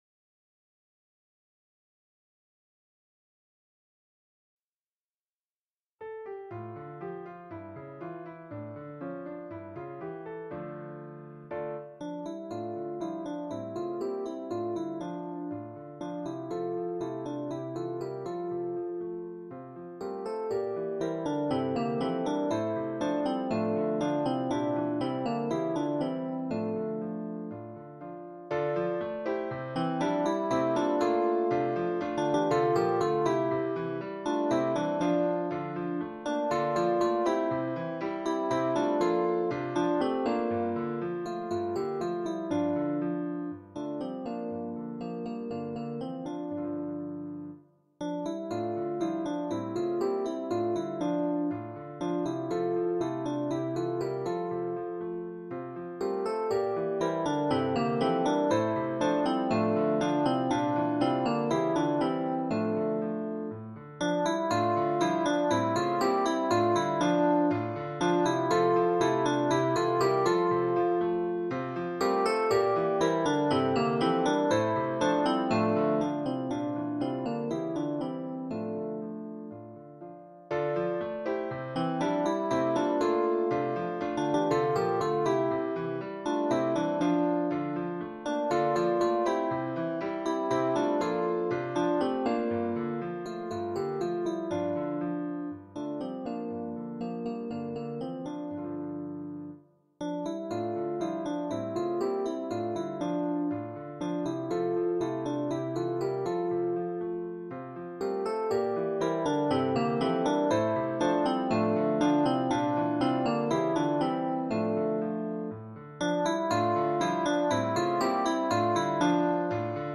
MP3 version instrumentale
Soprano